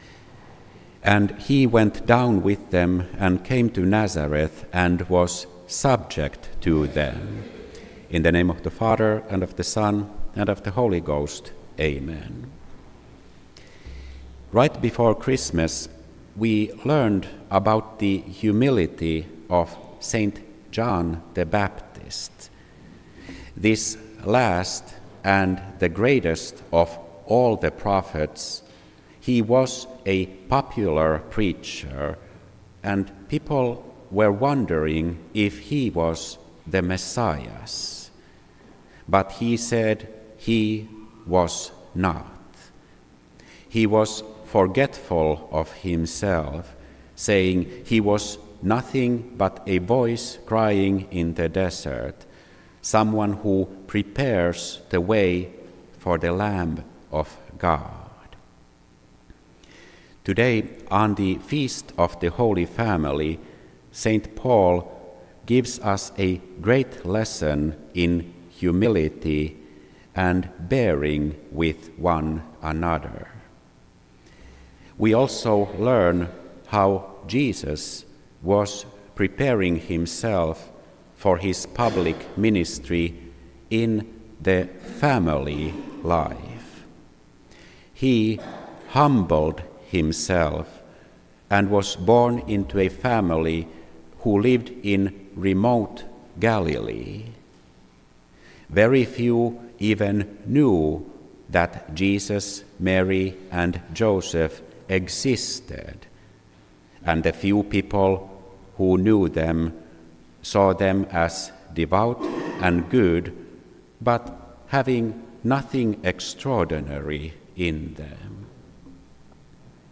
This entry was posted on Sunday, January 11th, 2026 at 4:10 pm and is filed under Sermons.